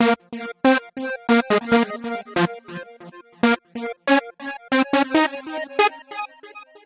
恍惚的舞蹈钢琴
描述：简单的小钢琴旋律，后面有锯齿状的合成器。
Tag: 138 bpm Trance Loops Piano Loops 2.35 MB wav Key : Unknown